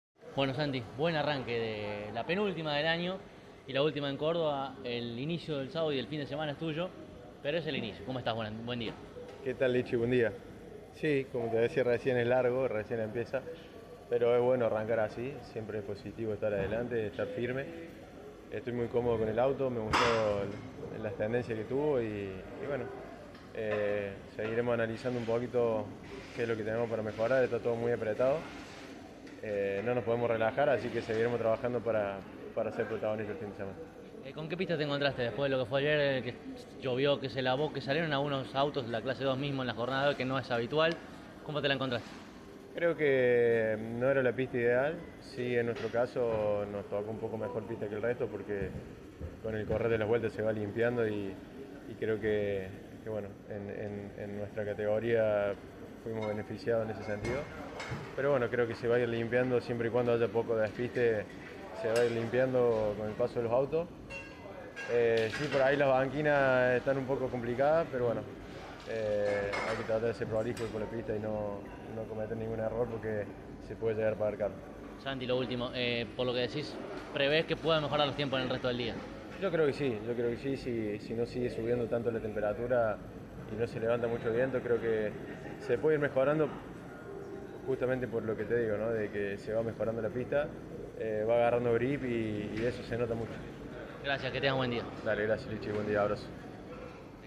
en diálogo con CÓRDOBA COMPETICIÓN: